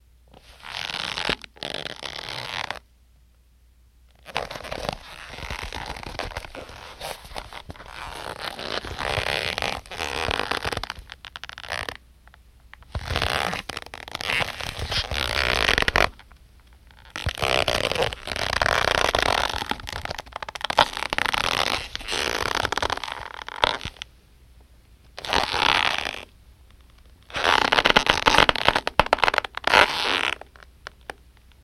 Leather Shoe Squeaks Long Take